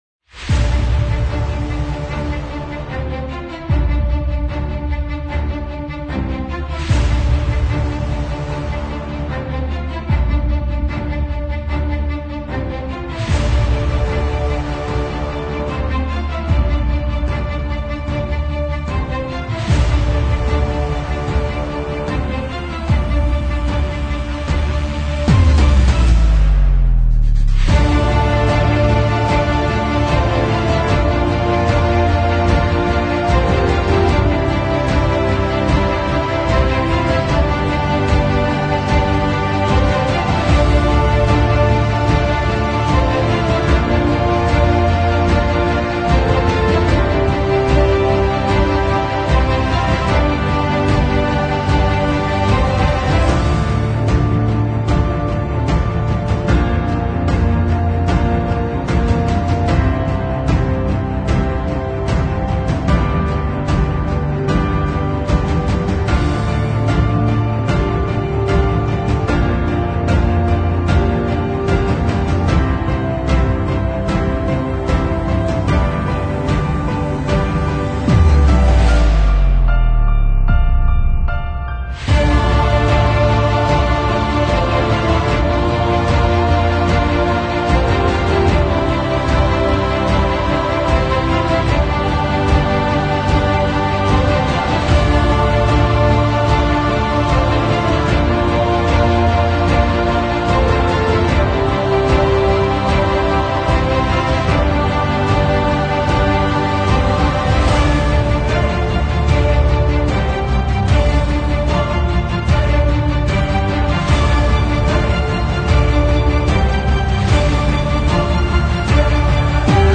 描述：强大的史诗管弦乐曲与混合效果。